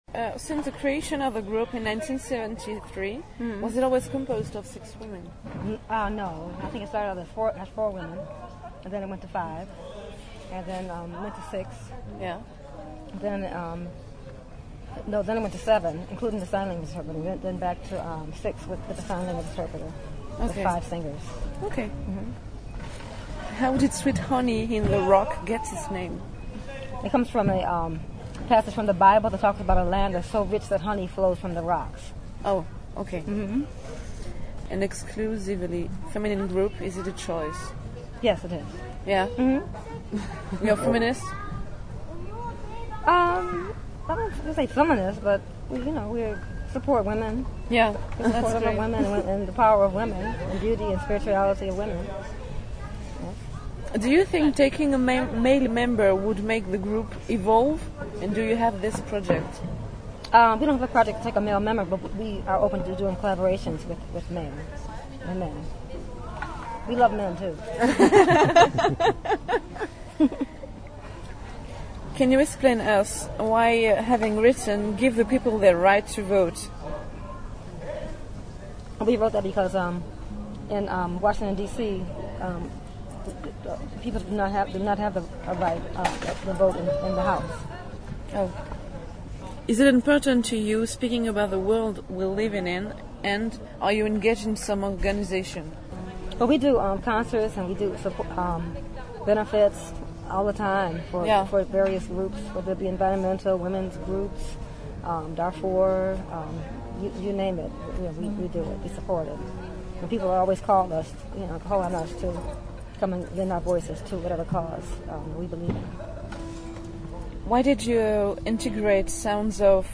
Interview de Sweet honey on the rock
Interview réalisé lors de leur passage à Marciac 2007.
marciac 2007 sweet honey on the rock web.mp3